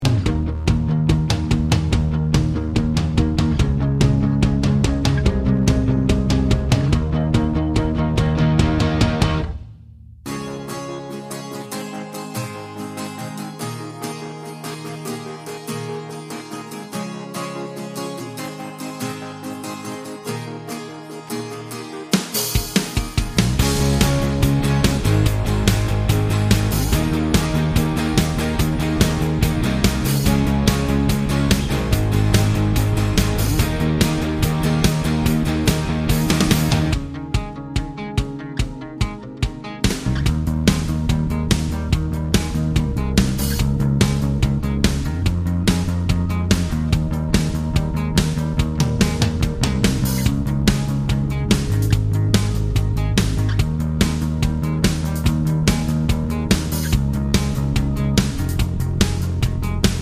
no Backing Vocals Rock 3:51 Buy £1.50